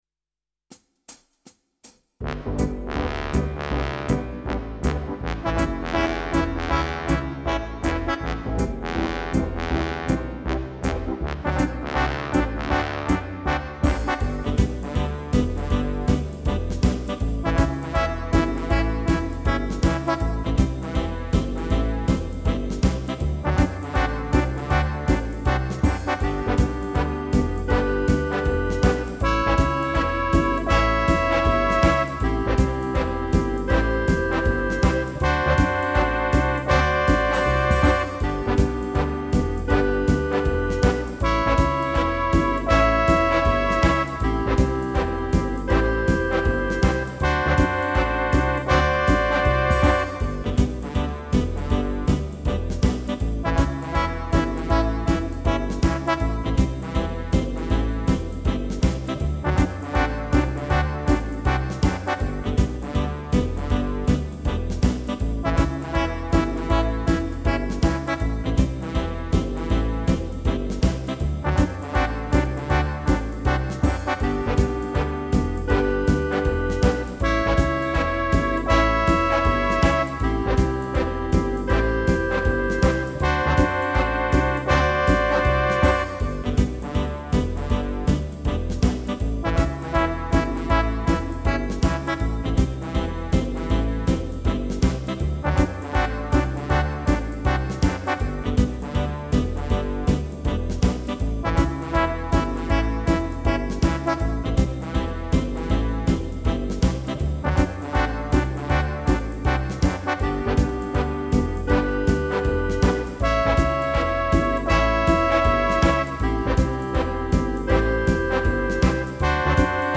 Home > Music > Jazz > Bright > Smooth > Medium